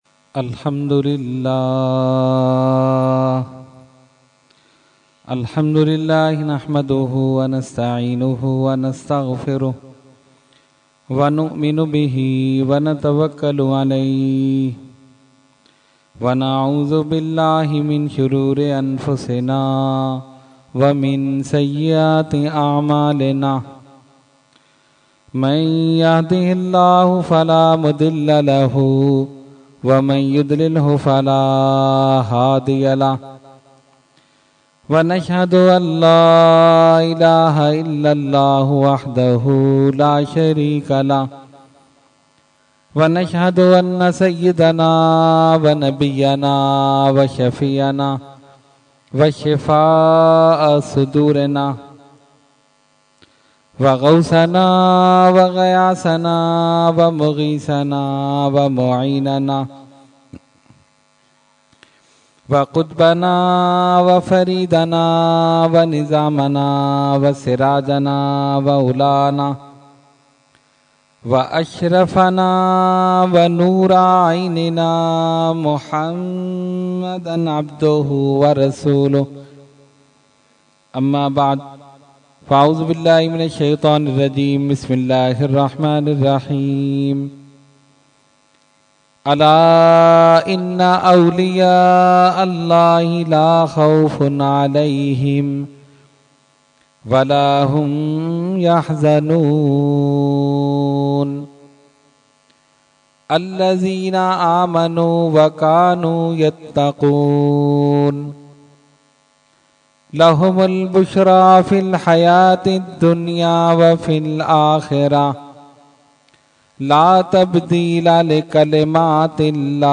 Category : Speech | Language : UrduEvent : 11veen Shareef 2014